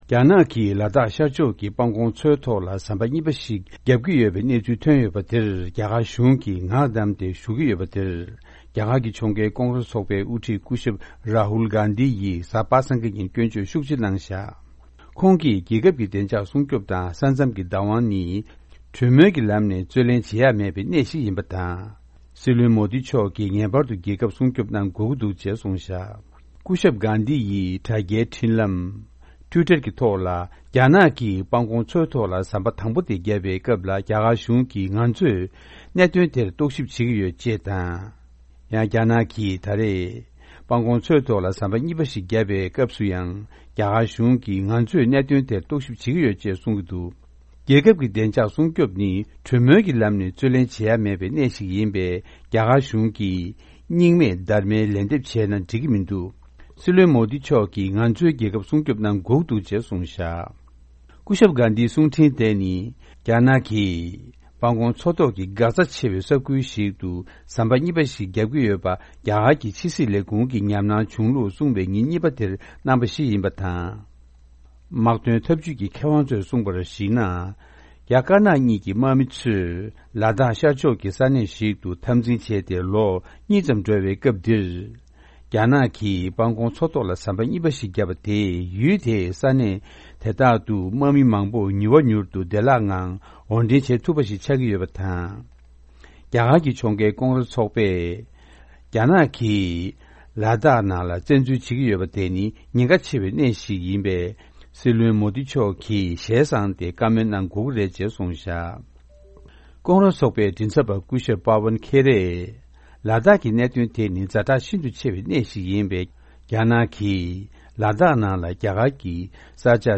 སྙན་སྒྲོན་ཞུ་ཡི་རེད།།